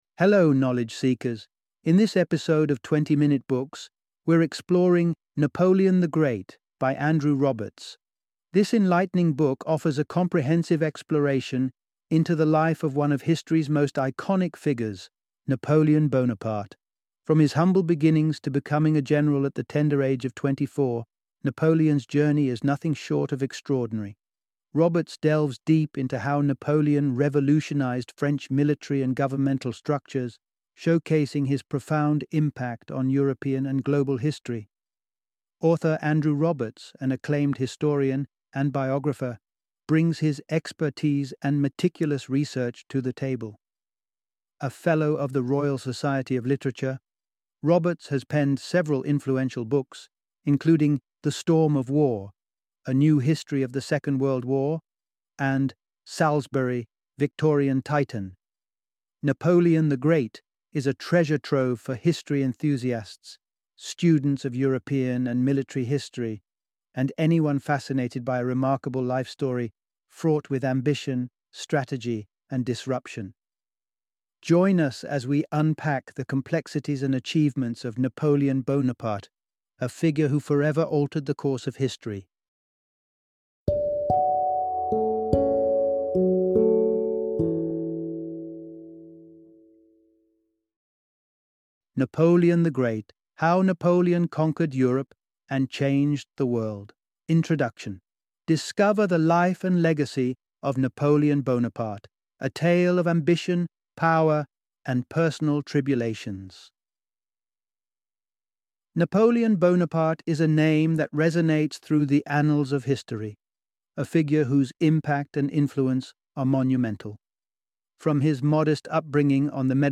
Napoleon the Great - Audiobook Summary